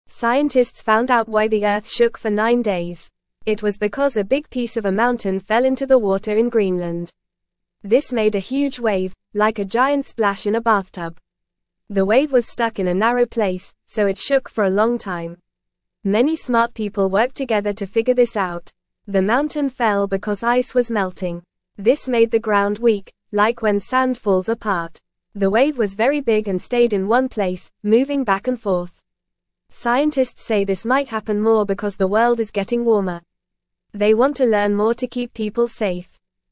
Fast
English-Newsroom-Beginner-FAST-Reading-Big-Wave-Shook-Earth-for-Nine-Days-Straight.mp3